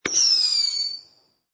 Tiếng Hú của Pháo Hoa bắn lên trời
Thể loại: Hiệu ứng âm thanh
Description: Tiếng Hú của Pháo Hoa bắn lên trời là tiếng hú như tiếng rít lên khi có người bắn pháo hoa lên trời, tiếng nổ nhanh kèm theo tiếng rít vang vọng vào không trung là âm thanh bắn pháo hoa, âm thanh sử dụng ngày xưa trong chiến trường để tình báo.
tieng-hu-cua-phao-hoa-ban-len-troi-www_tiengdong_com.mp3